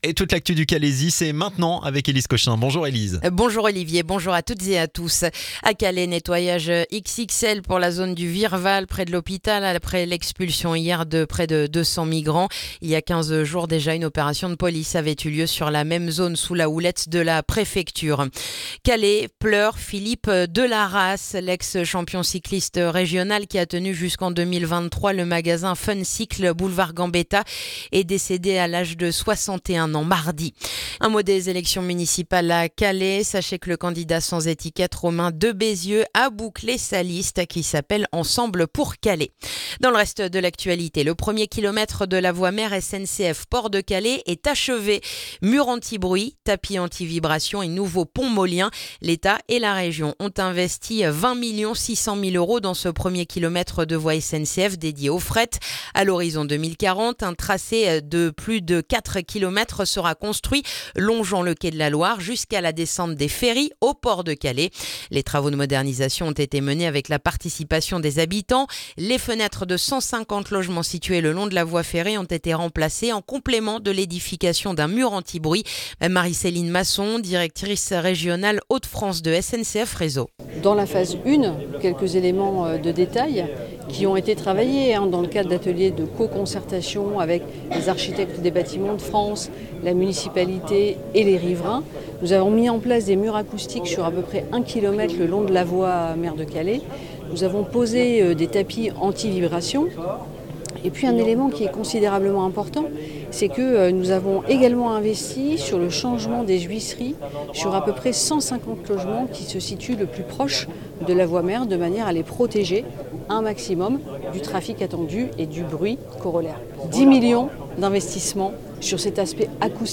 Le journal du jeudi 12 février dans le calaisis